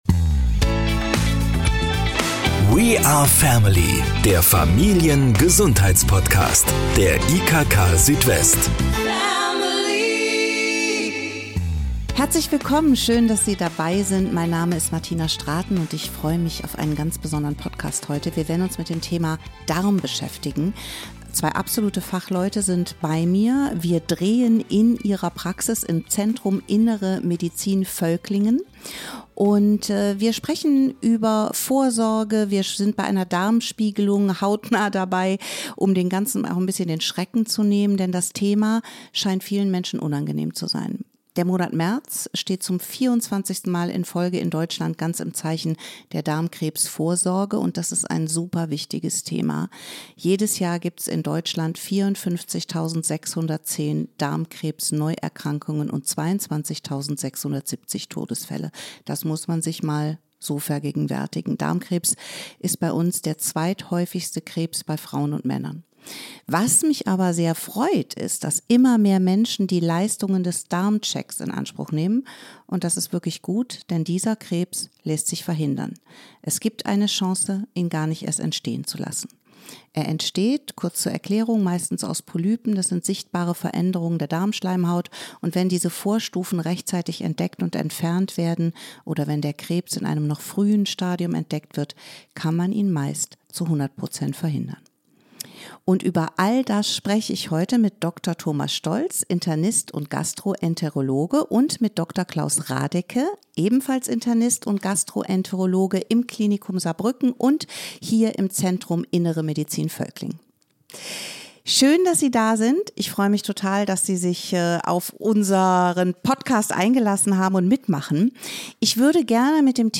Dabei kann man diesen Krebs, wenn man ihn früh erkennt, zu einhundert Prozent verhindern. Wir waren im Saarbrücker Winterberg-Klinikum bei einer Darmspiegelung dabei.